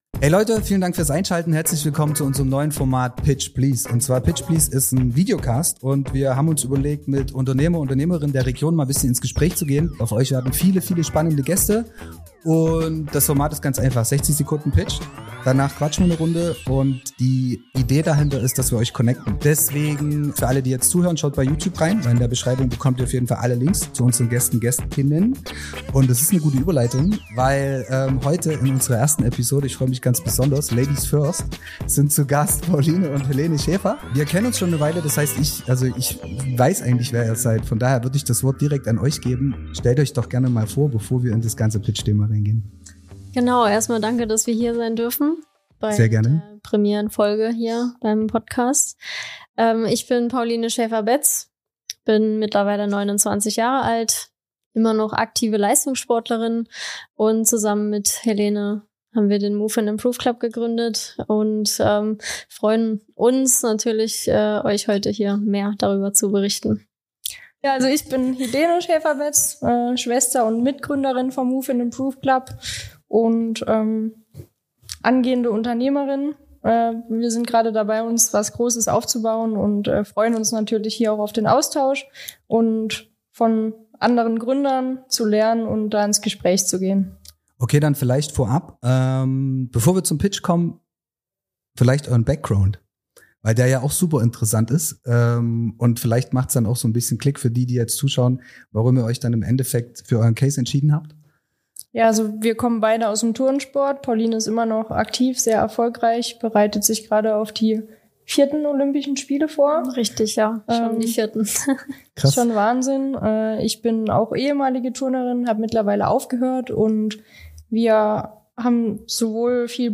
60 Sekunden Pitch  Rund 30 Minuten Talk  Im pitch please. Podcast bringen Gründer und Gründerinnen ihr Business in einer Minute auf den Punkt.